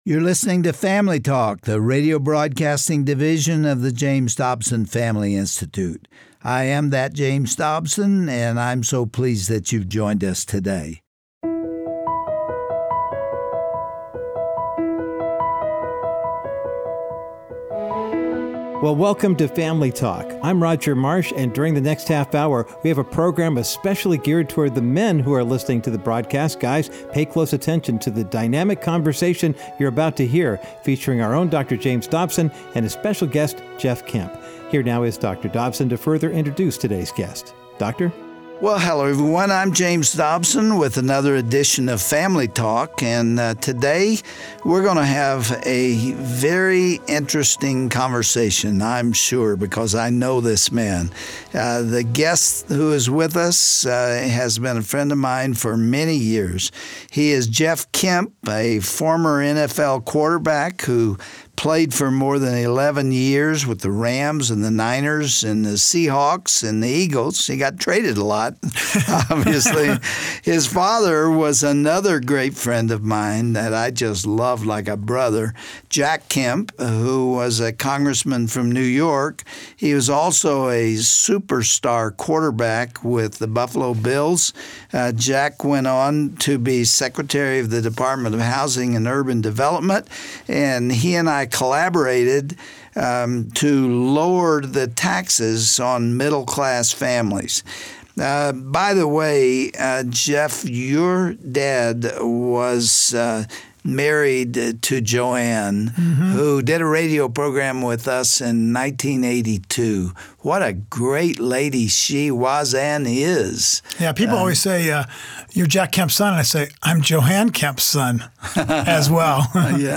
On today’s edition of Family Talk, Dr. James Dobson talks with Jeff as he shares how the trials in our lives compare to that of a blitz in football, when it appears that the whole world is out to get you and take you down! Learn of the hope we can have when we turn these adversities or blitzes, as Jeff says, over to Jesus.
Host Dr. James Dobson
Guest(s):Jeff Kemp